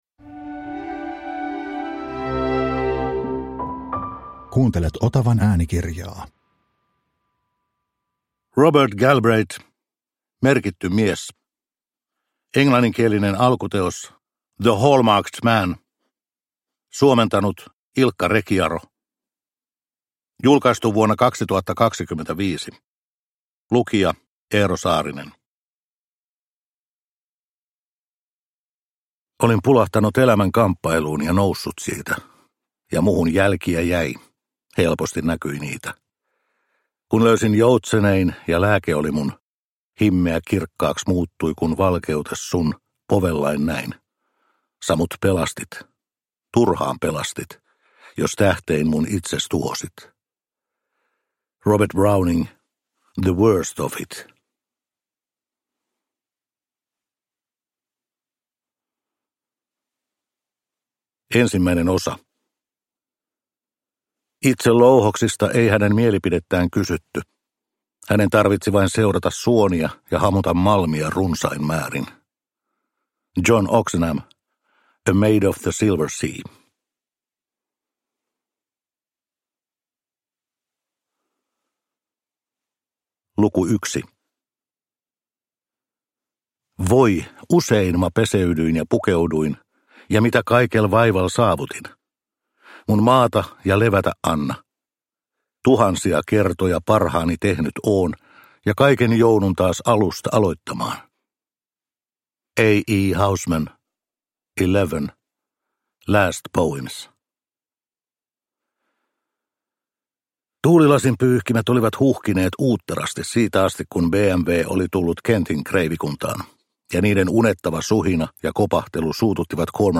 Merkitty mies (ljudbok) av Robert Galbraith